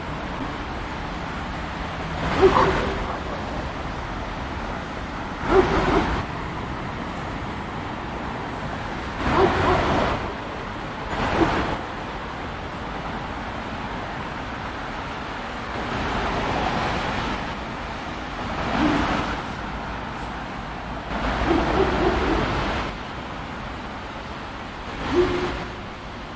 狗叫声和交通噪音
描述：hundebellen verkehr stadt狗吠狗吠声城市树皮交通
Tag: 树皮 交通 树皮 吠叫 城市 城区 verkehr hundebellen